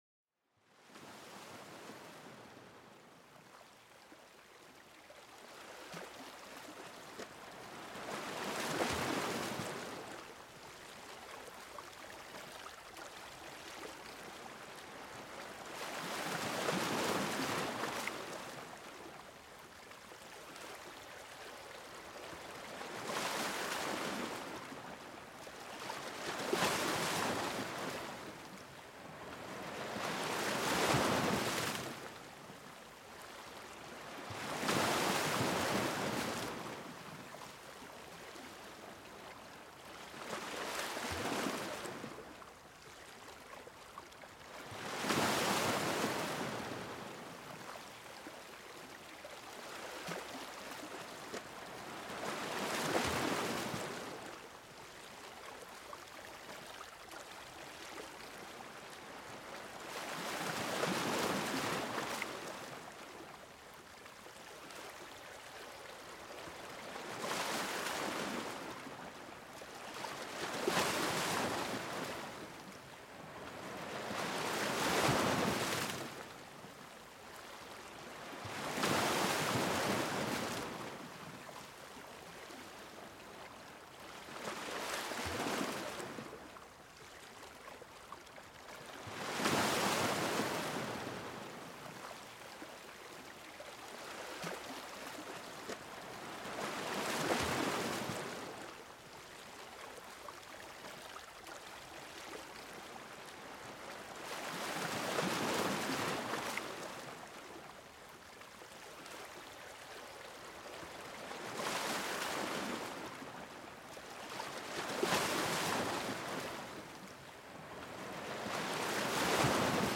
Le bercement des vagues pour un apaisement profond